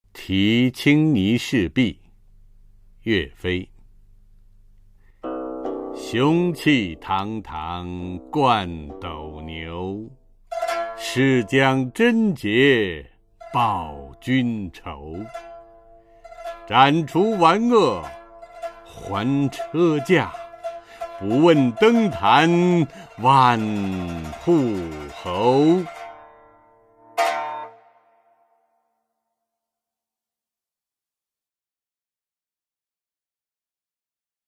[宋代诗词诵读]岳飞-题青泥市壁 宋词朗诵